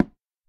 wood2.ogg